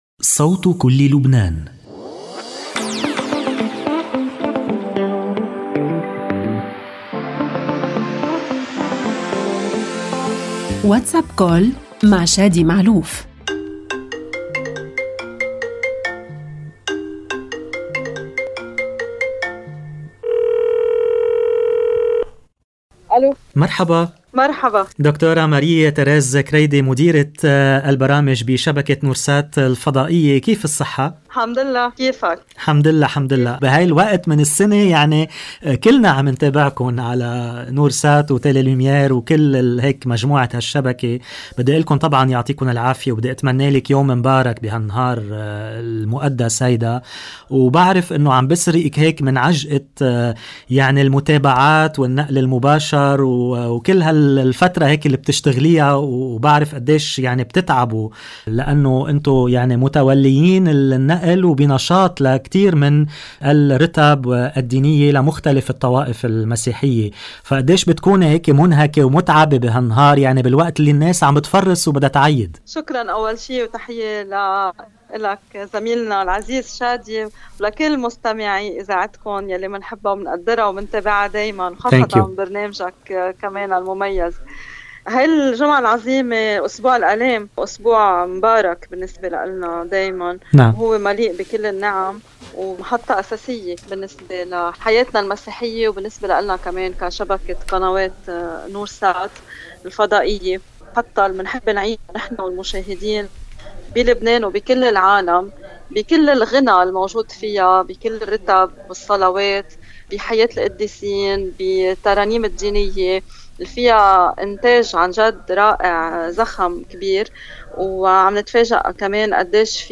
WhatsApp Call